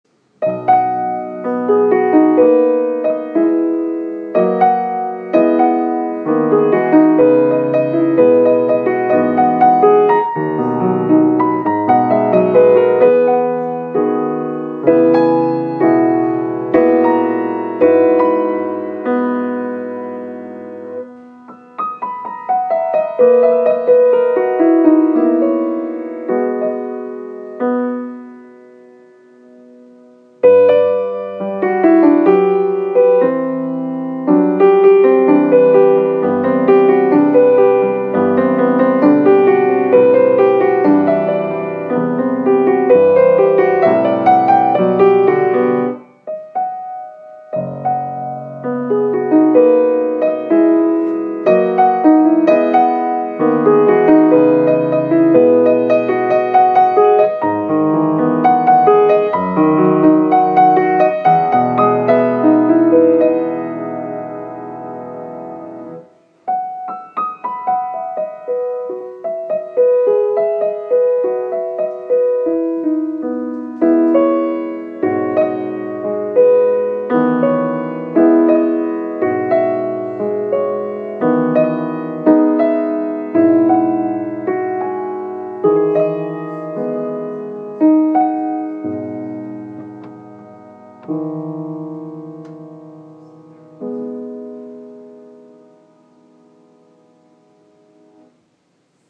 It is essentially lyric and utterly diatonic.